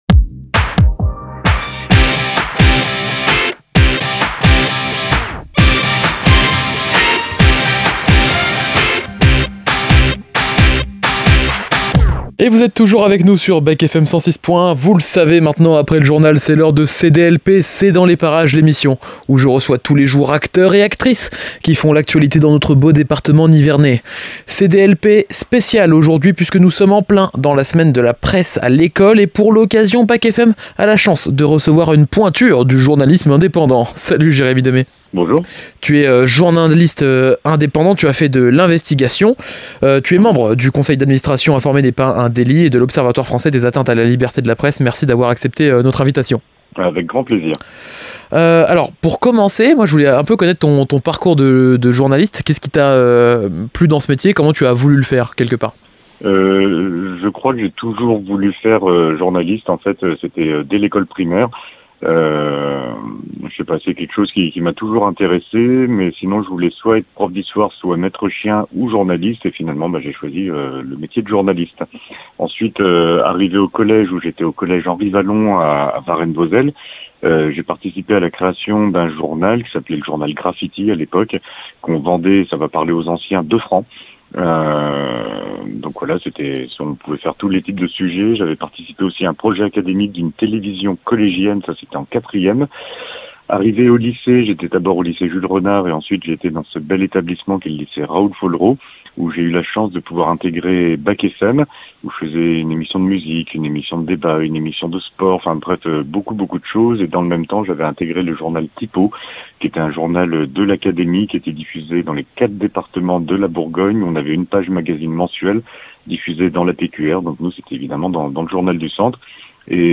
C'est dans les parages - Interview